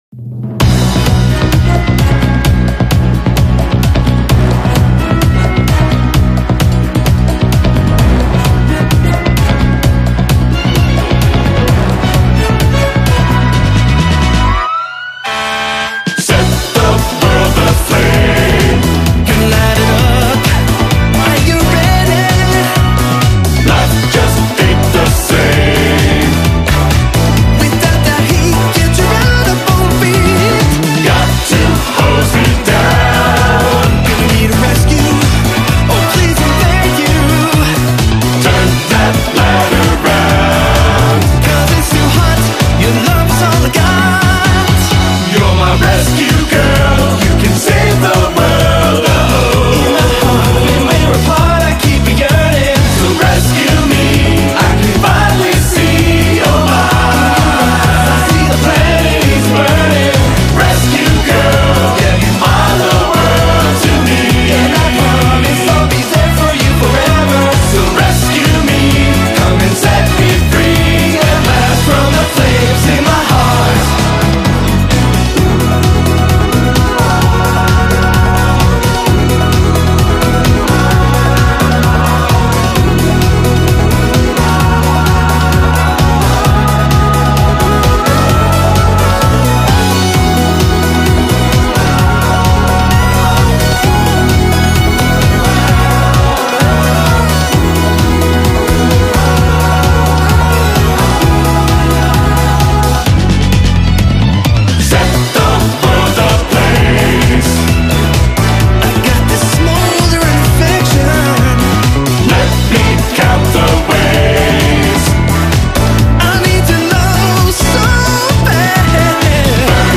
Effed Up Music